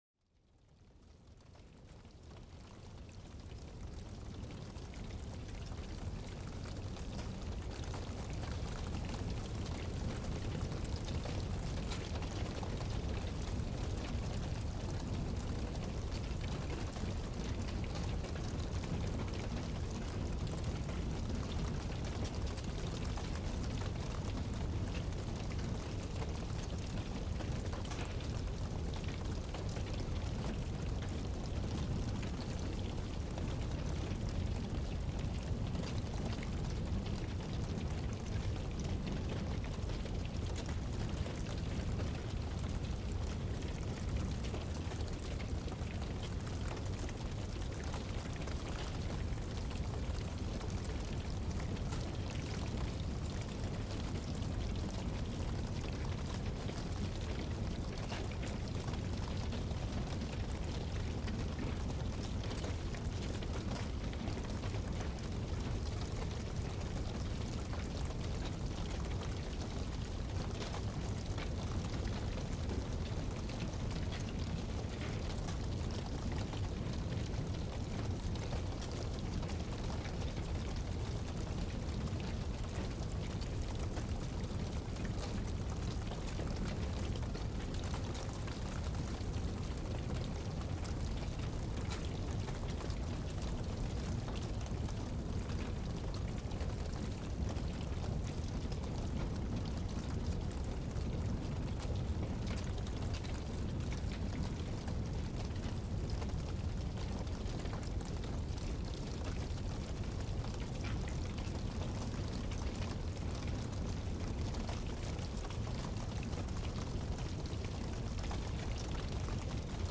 Llamas Calmantes: Relajación al sonido del fuego de campamento y beneficios del relajamiento